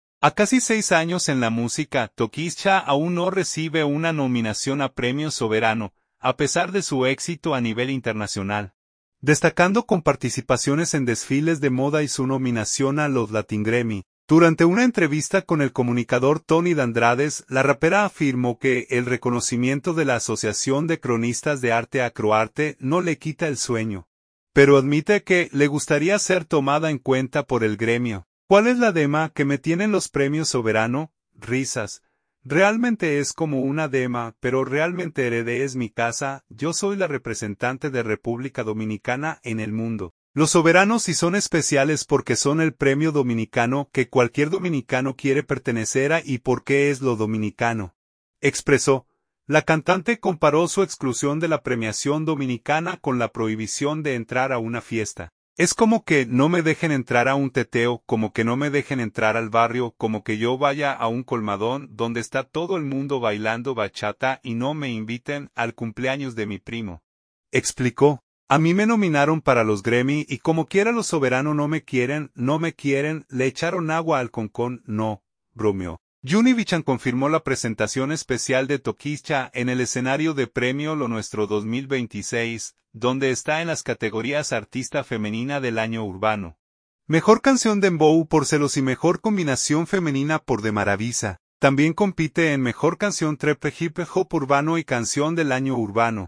Durante una entrevista con el comunicador Tony Dandrades, la rapera afirmó que el reconocimiento de la Asociación de Cronistas de Arte (Acroarte) no le quita el sueño, pero admite que le gustaría ser tomada en cuenta por el gremio.